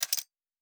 Metal Tools 04.wav